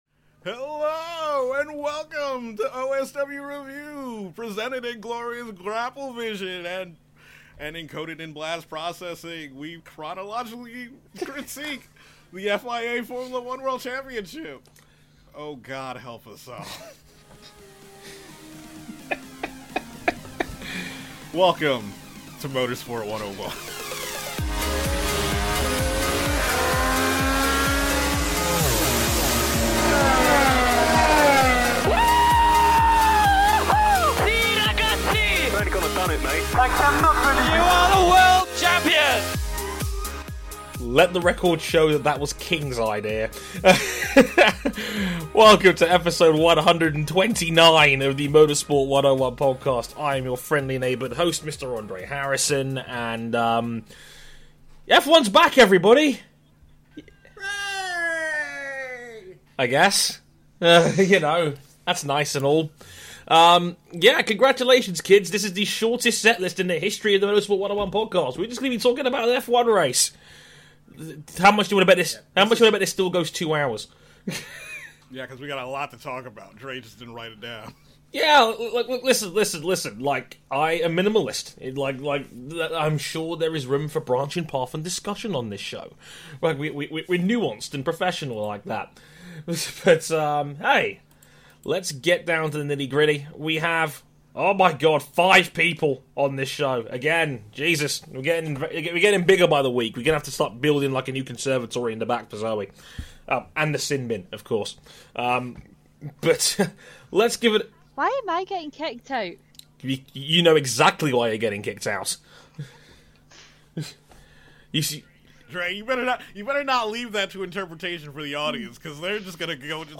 And points to anyone who can tell me what Pokemon tune that is!